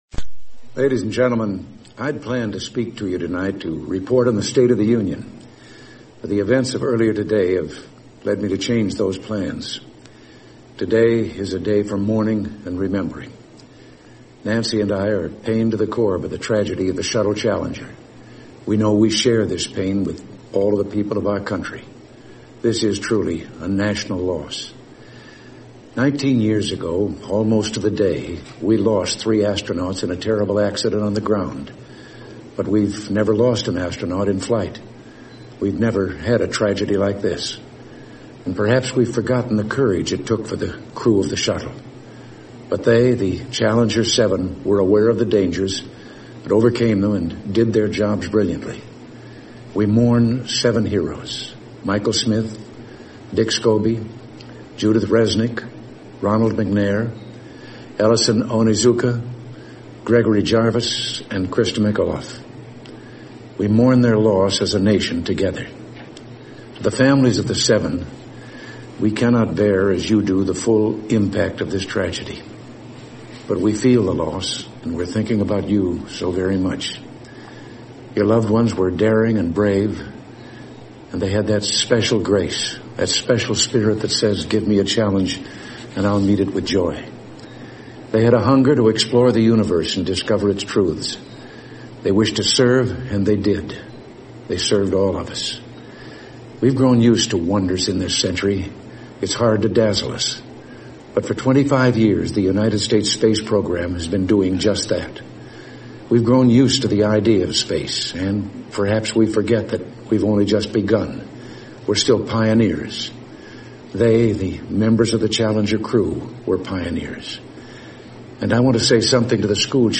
美国百大英语演讲 The Space Shuttle "Challenger" Tragedy Address by 罗纳德.里根 听力文件下载—在线英语听力室